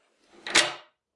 Door Handle and Lock
描述：Jiggling the handle of a door handle. Also, locking and unlocking deadbolt on same door. Indoor. Recorded on Zoom H2
标签： door bolt dead deadbolt lock fieldrecording handle
声道立体声